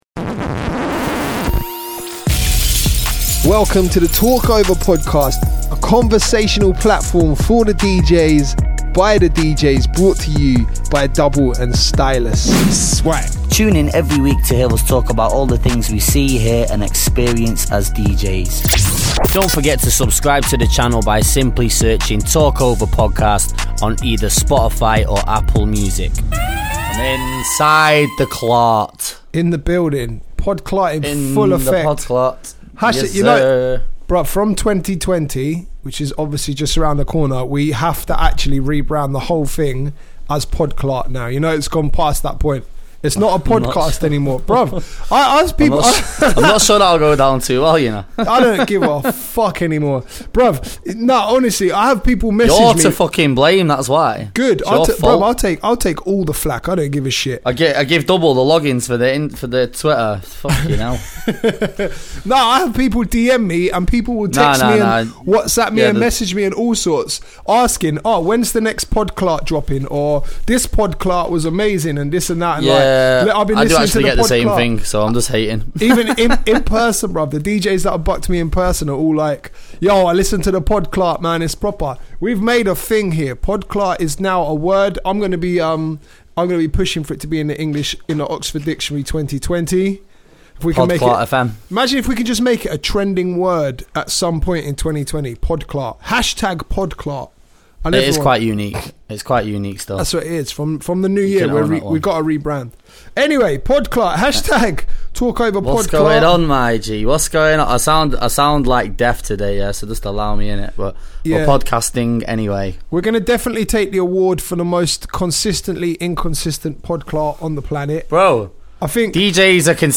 two DJs from the UK & we've decided that there needs to be an outlet for various topics relating to the DJ world… Things that ALL DJs want or need to be talking about.